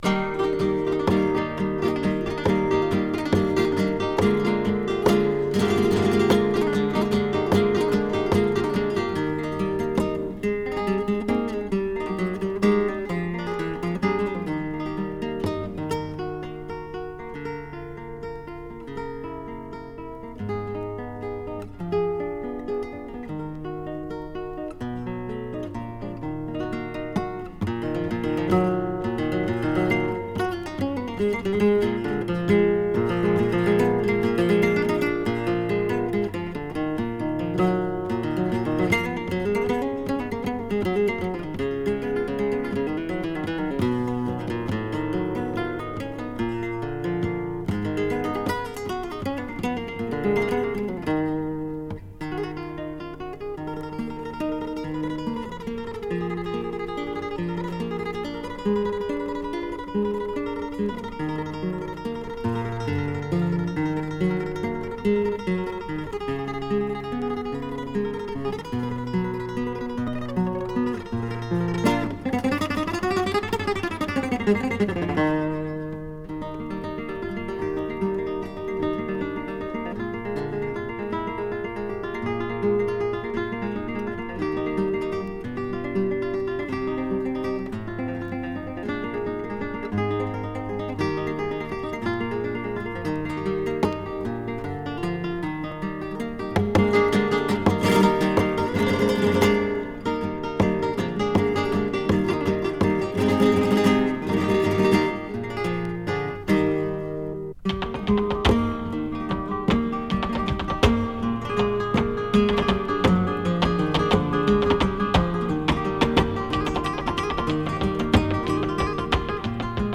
alegrías